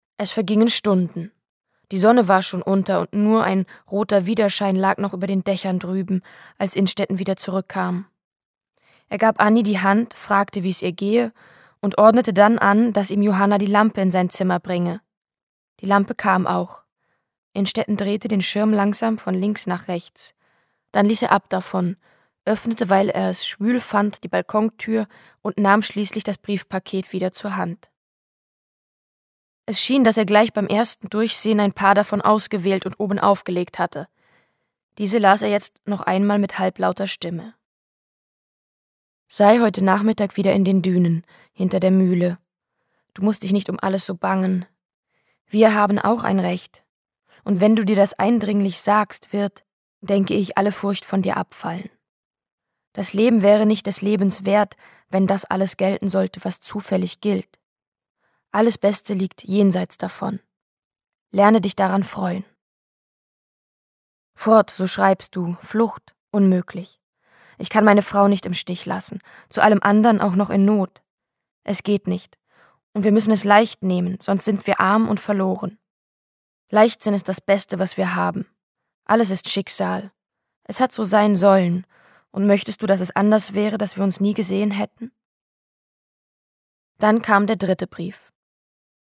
Die Lesung: 4 CDs
Julia Jentsch (Sprecher)